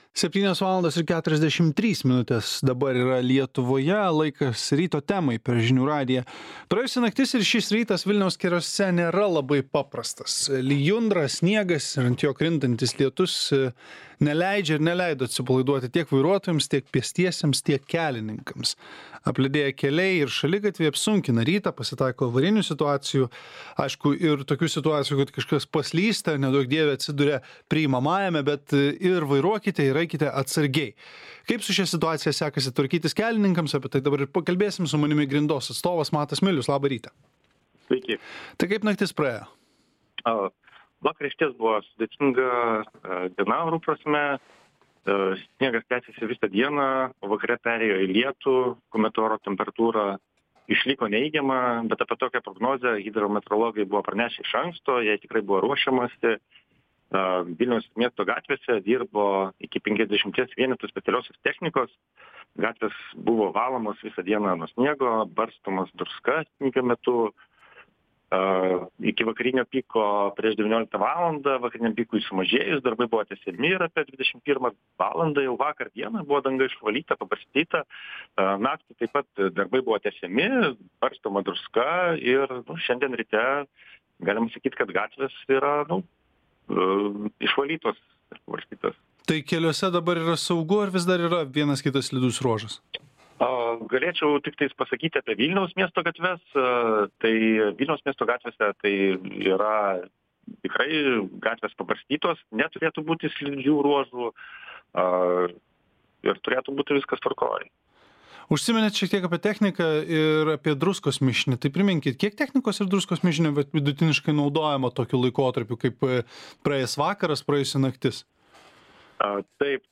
Rytinė eismo saugumo ir hidrometeorologinė apžvalga su „Grindos" ir hidrometeorologijos tarnybos atstovais.